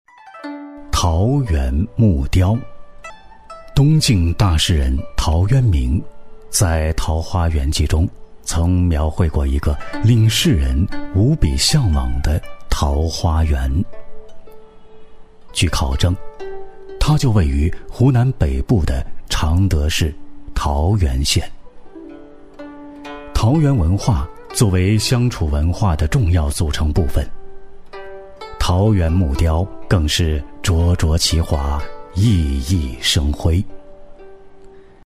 纪录片男182号（桃源木雕
娓娓道来 文化历史
磁性稳重男音，擅长考古纪录片解说、文化讲述、旁白等题材。